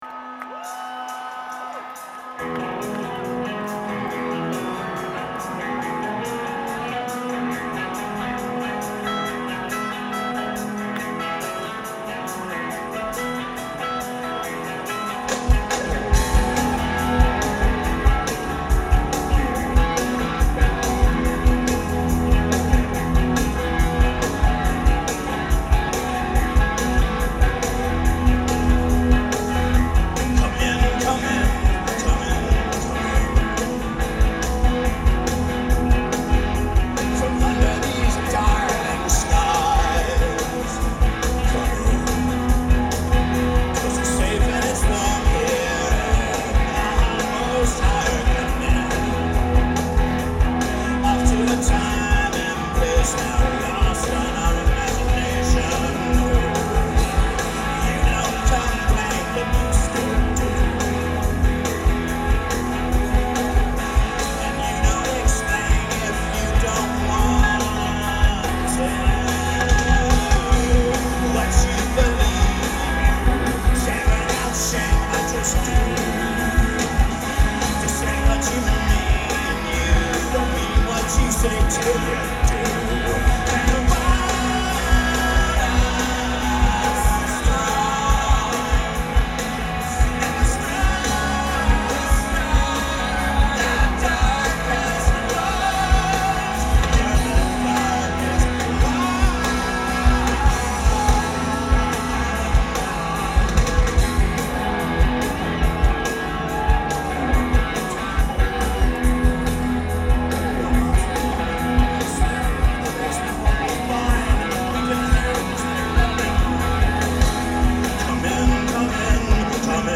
Live In 2003
June 21 2003 - Toronto, ON - Skydome (SARSfest)
Source: Audience